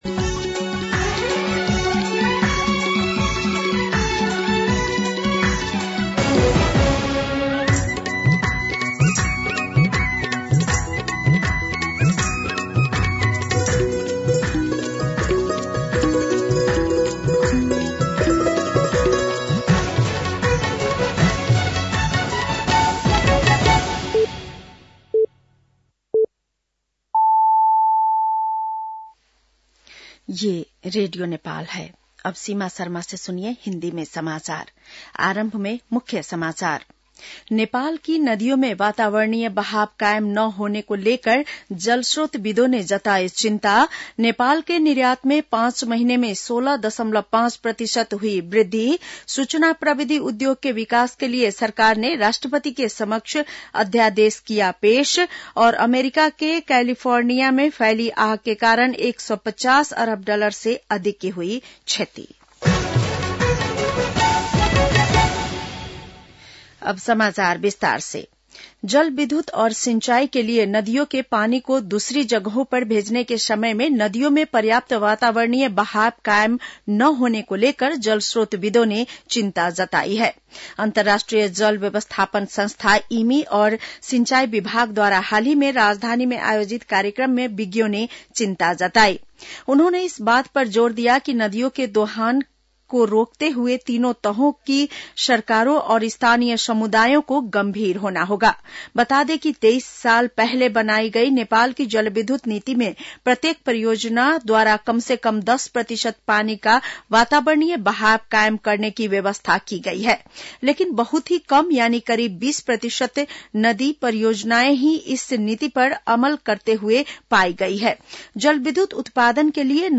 बेलुकी १० बजेको हिन्दी समाचार : २८ पुष , २०८१
10-PM-Hindi-News-.mp3